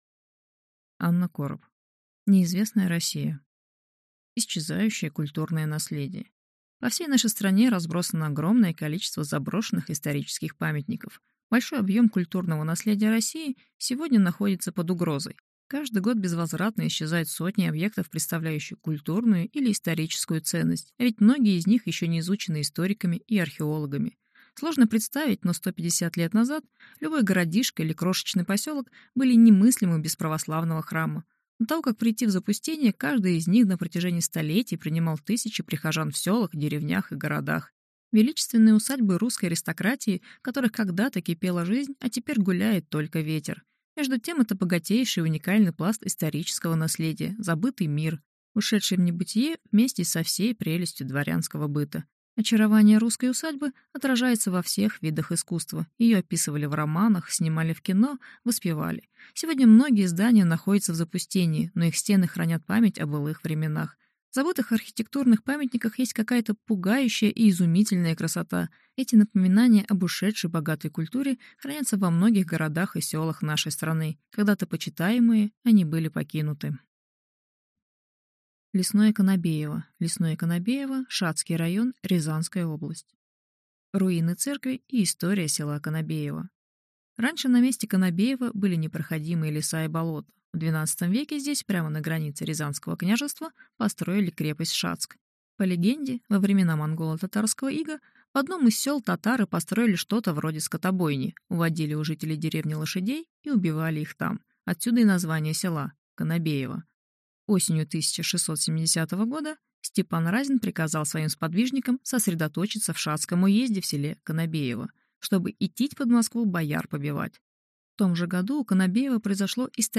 Аудиокнига Неизвестная Россия | Библиотека аудиокниг